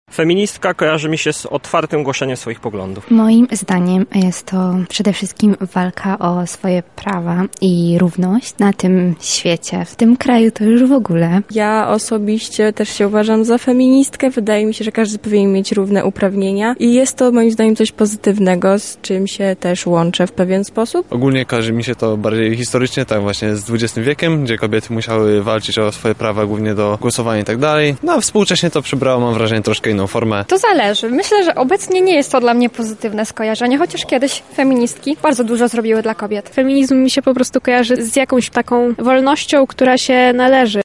Zapytaliśmy lublinian z czym dziś kojarzy im się feminizm:
sonda feminizm